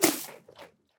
sounds / mob / goat / eat2.ogg
eat2.ogg